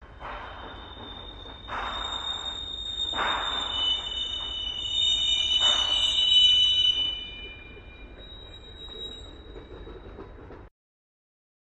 Train Braking, Distant High Squeals With Harder Chuffs